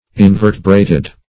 Invertebrated \In*ver"te*bra`ted\